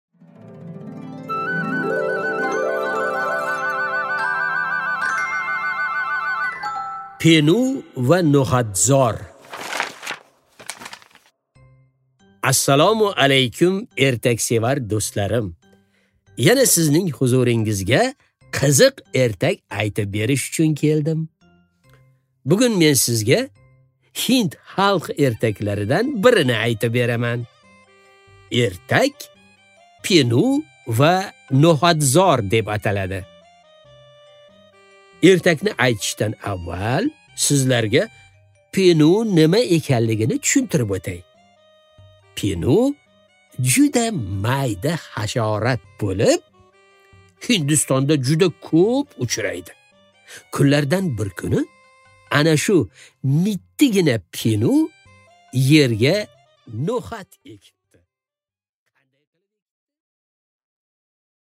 Аудиокнига Pеnu va no'хatzor